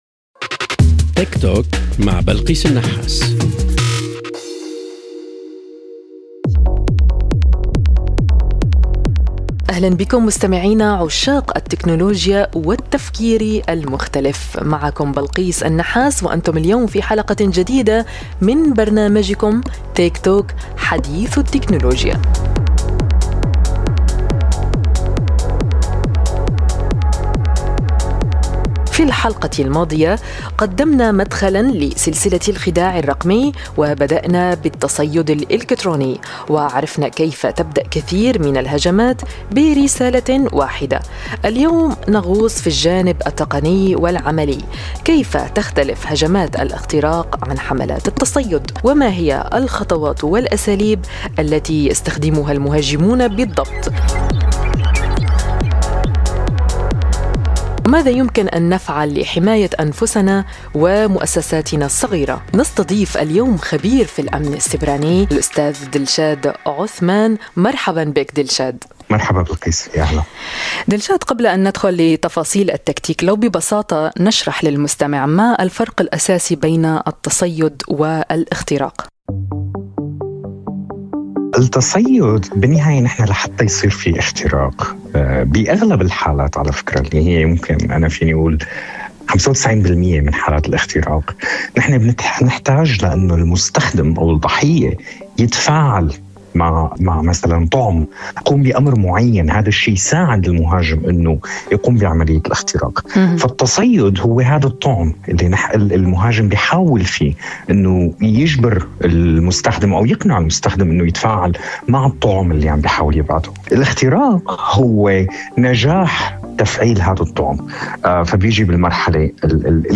هل تعرفون كيف تبدأ عملية اختراق حقيقية؟ ليست ضغطة واحدة فقط، بل سلسلة خطوات ذكية تبدأ بجمع المعلومات عنك، مروراً بخداعك برسالة مزيفة، وصولاً إلى السيطرة على بياناتك أو أجهزتك. في هذا الحوار